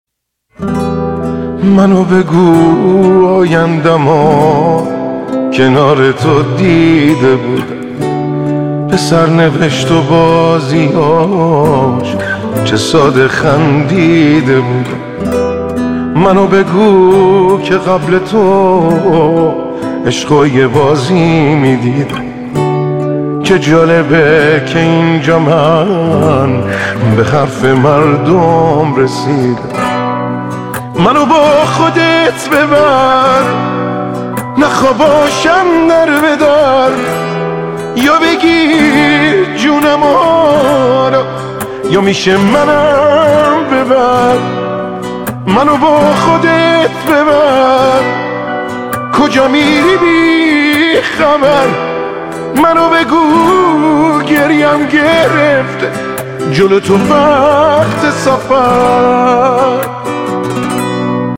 عاشقانه و جذاب